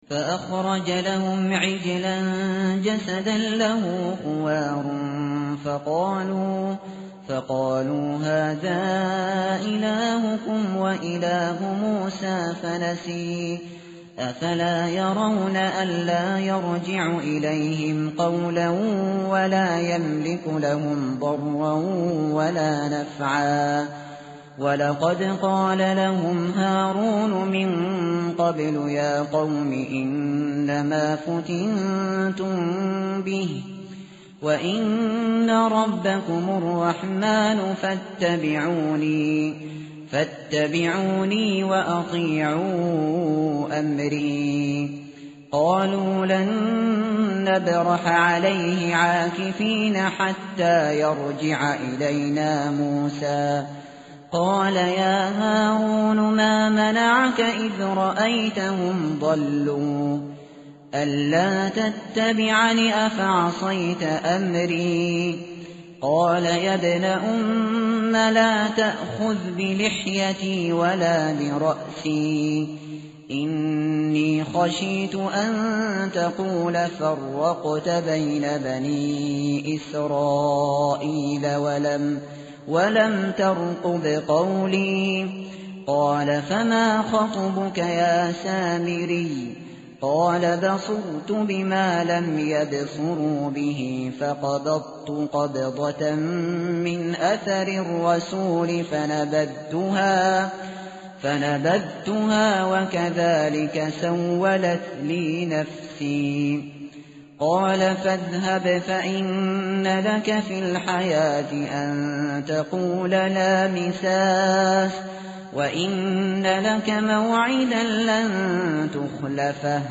متن قرآن همراه باتلاوت قرآن و ترجمه
tartil_shateri_page_318.mp3